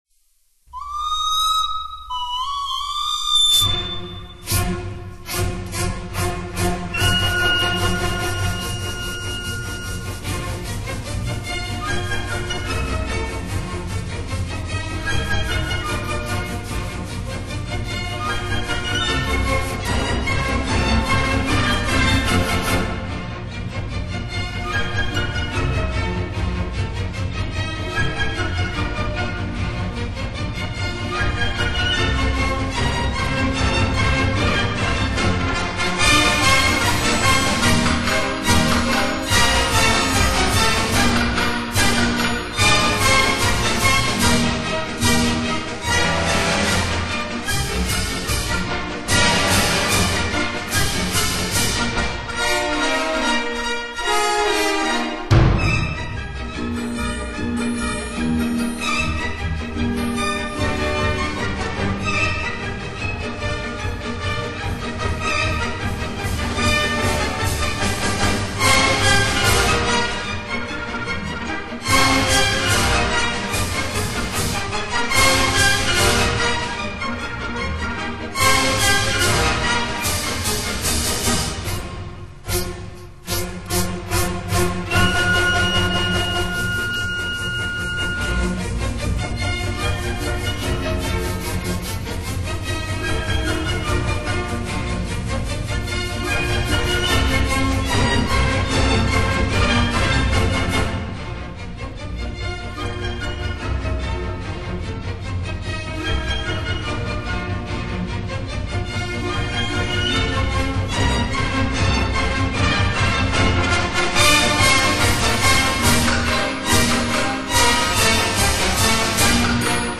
正宗维也纳圆舞曲